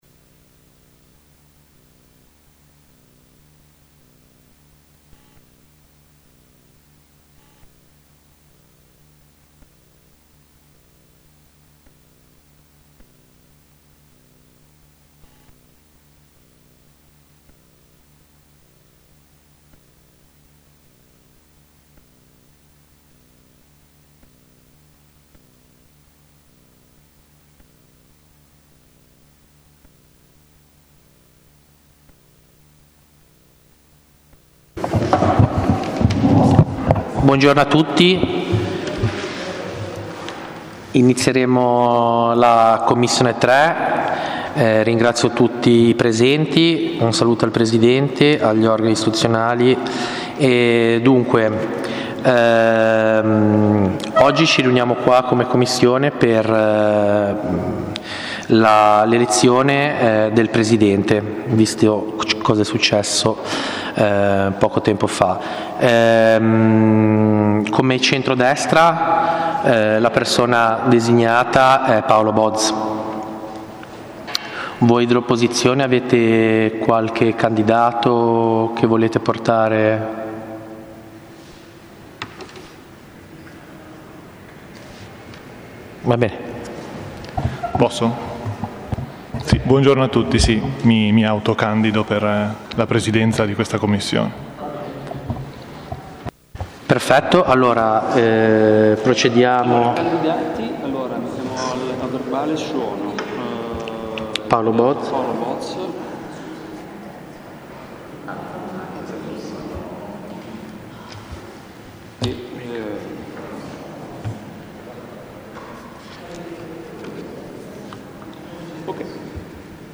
Piazza Manzoni, 1 - Sala del Consiglio
Audio seduta: